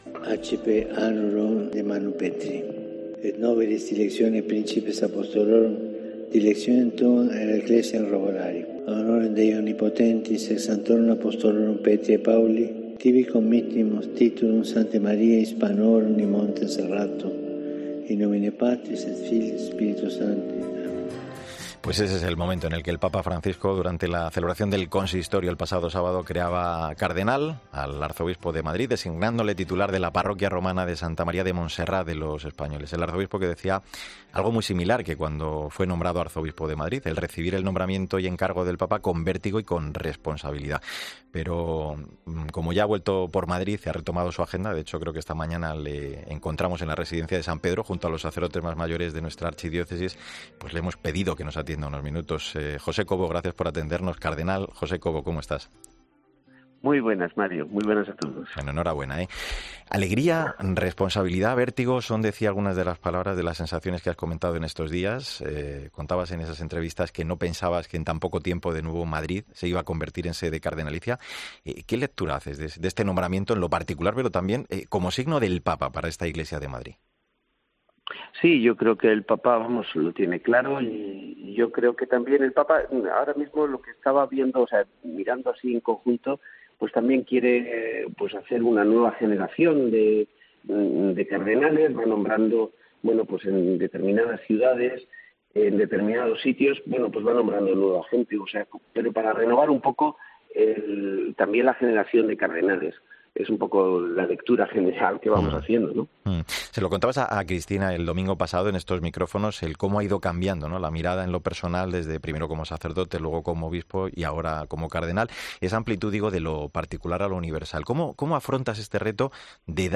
El arzobispo de Madrid ha sido entrevistado este viernes en 'El Espejo' y ha valorado el Sínodo que se está celebrando en Roma: "Es un momento muy...